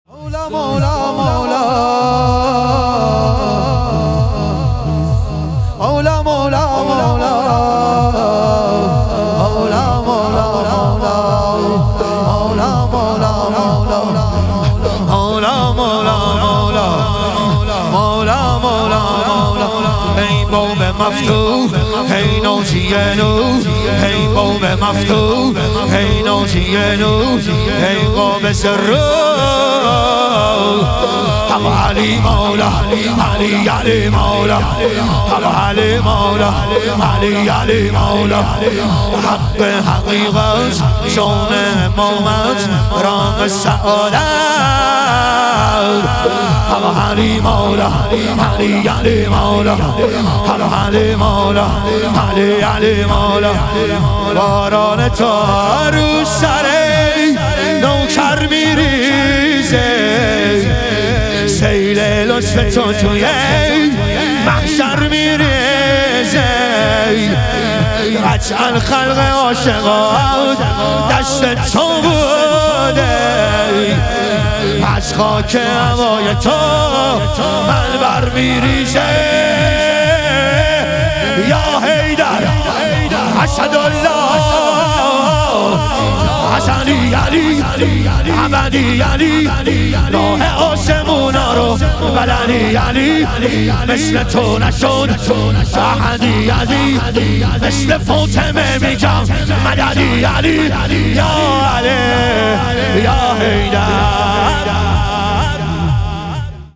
شام غریبان حضرت زهرا علیها سلام - شور